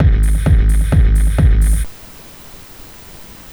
This sound wav plays a short drum beat.